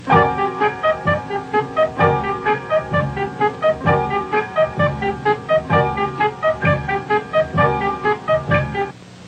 Reduced quality: Yes